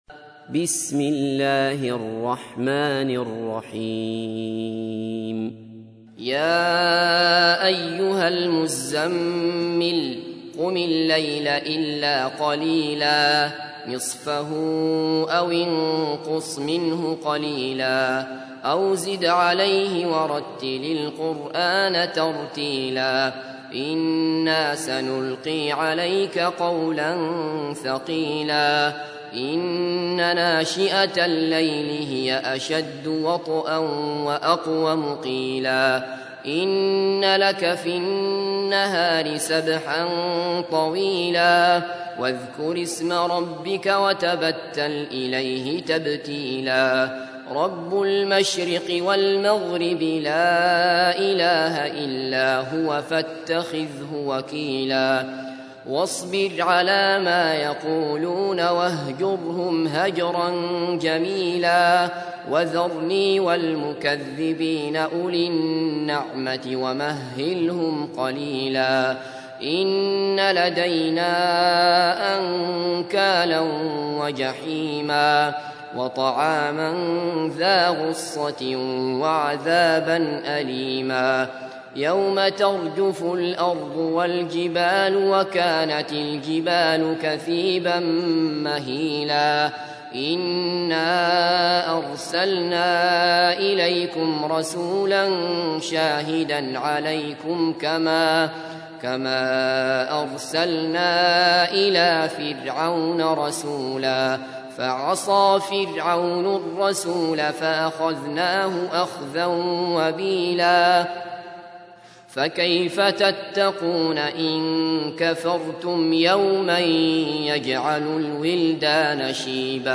تحميل : 73. سورة المزمل / القارئ عبد الله بصفر / القرآن الكريم / موقع يا حسين